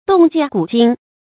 洞鉴古今 dòng jiàn gǔ jīn 成语解释 洞鉴：明察。深入透彻地了解历史与现实世事。